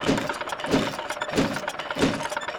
Windmill [loop].wav